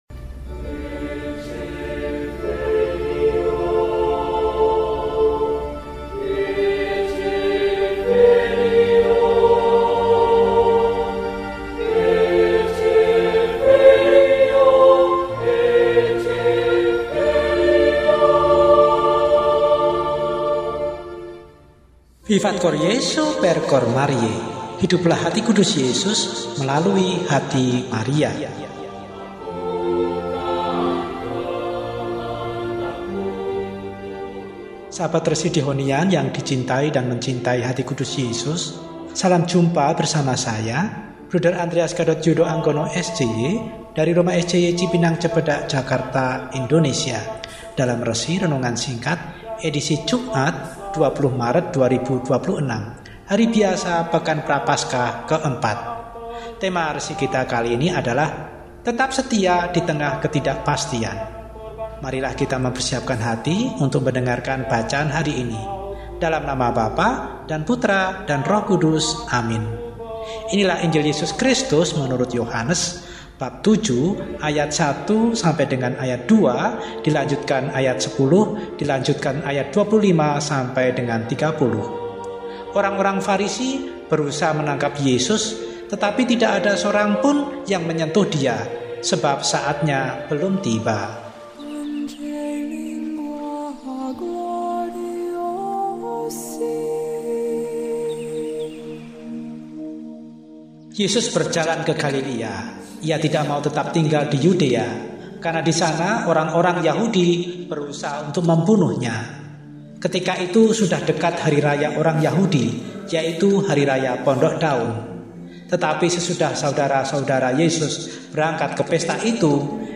Jumat, 20 Maret 2026 – Hari Biasa Pekan IV Prapaskah – RESI (Renungan Singkat) DEHONIAN